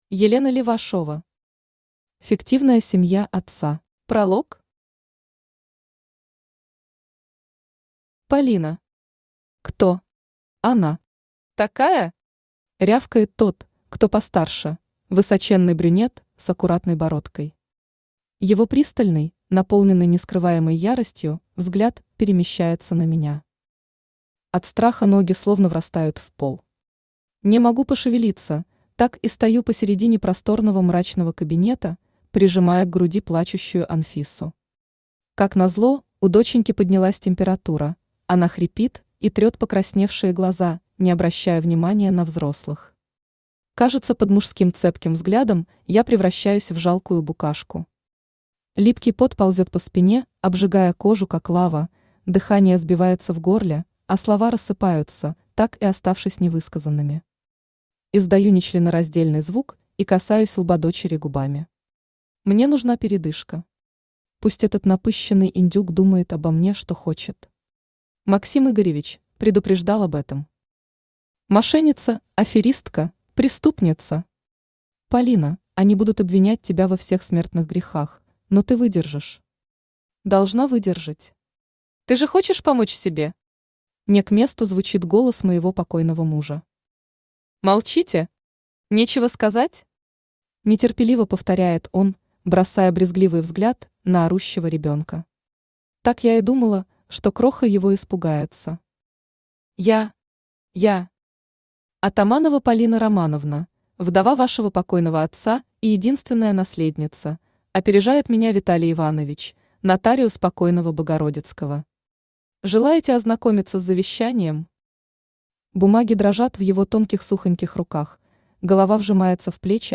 Аудиокнига Фиктивная семья отца | Библиотека аудиокниг
Aудиокнига Фиктивная семья отца Автор Елена Левашова Читает аудиокнигу Авточтец ЛитРес.